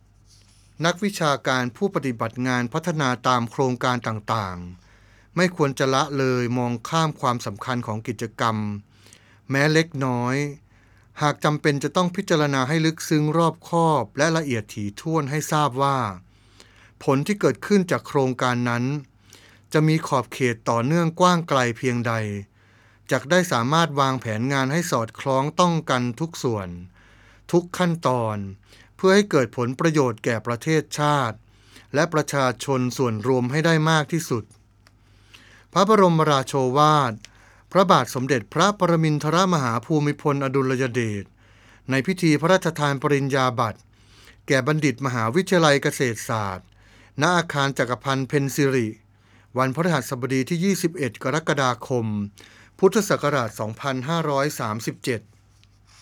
พระบรมราโชวาท
ในพิธีประสาทปริญญาบัตรและอนุปริญญาบัตร ของมหาวิทยาลัยเกษตรศาสตร์